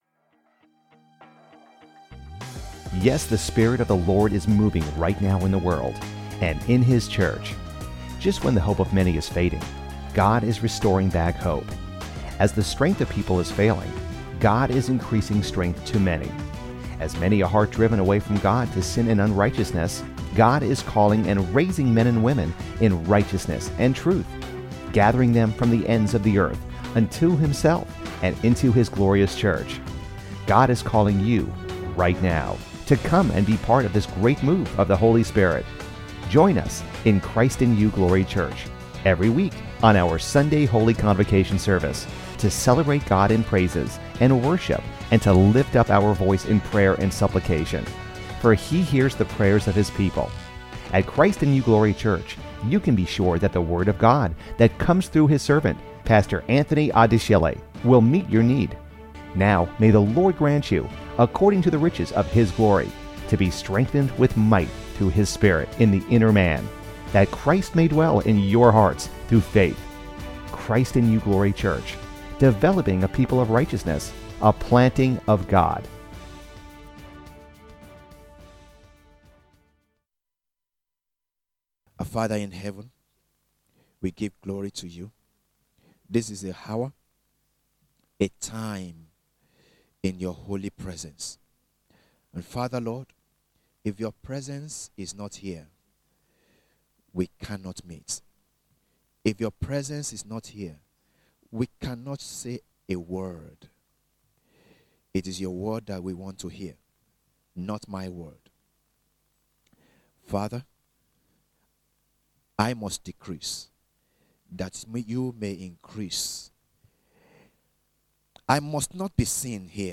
We constantly update and provide you with sermons and teachings for your nourishment and spiritual development.